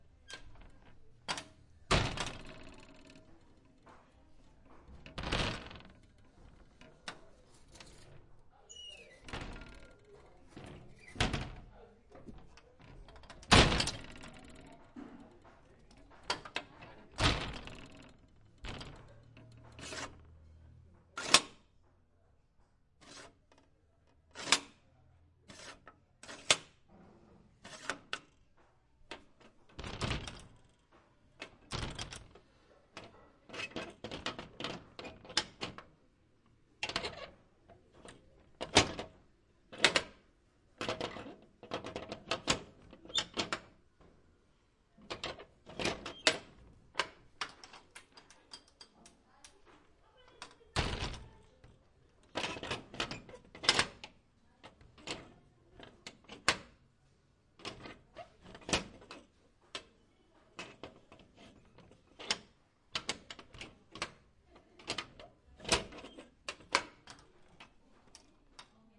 乌干达 " 金属厚重的地牢监狱门解锁打开关闭砰砰作响，舱门打开关闭滑行吱吱作响各种角度的声音
描述：金属重型牢牢监狱门解锁打开关闭嘎嘎声和舱口盖打开关闭幻灯片吱吱声各种外部视角onmic + bg宿舍厨房sounds1.wav